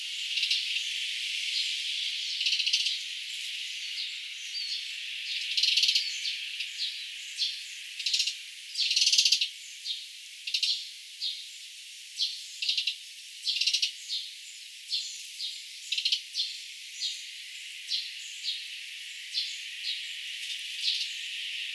pikkuvarpusemo äänteli näin
puhdistaessaan ruusuja ehkä kirvoista
pikkuvarpusemo_puhdistaa_ruusuja_ehka_kirvoista_aannellen.mp3